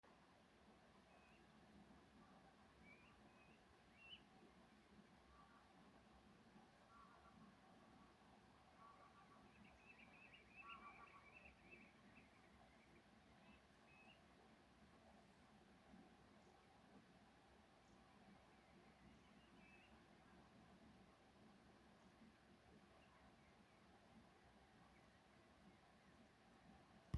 Putni -> Bridējputni ->
Smilšu tārtiņš, Charadrius hiaticula